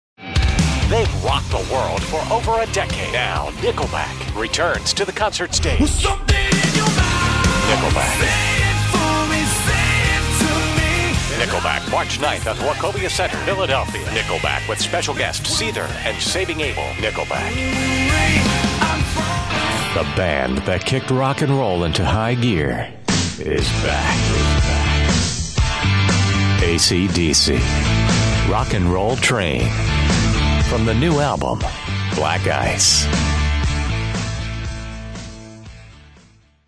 Male
The vibe is Warm, Friendly, Familiar, and Trustworthy, with just enough Edge to keep it interesting.
Music Promos
Rock Compilation